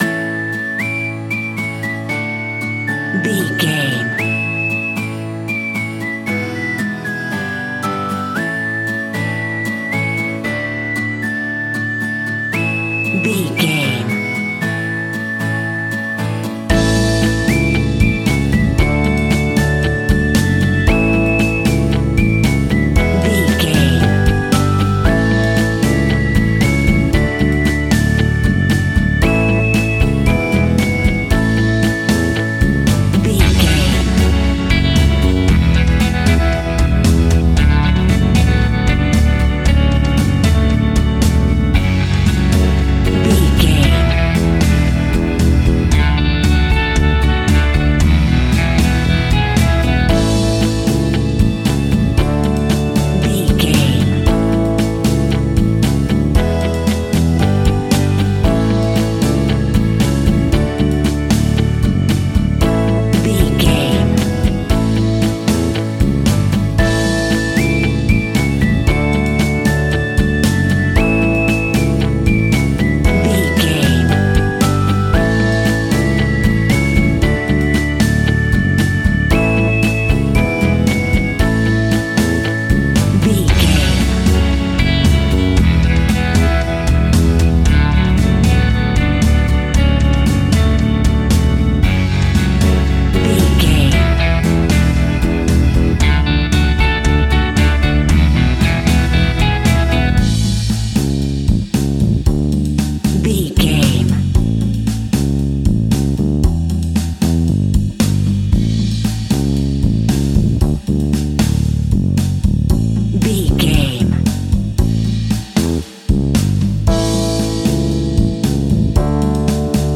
Ionian/Major
pop music
electro pop
pop rock
Sunshine pop
happy
peppy
upbeat
bright
bouncy
drums
bass guitar
electric guitar
keyboards
hammond organ
acoustic guitar
percussion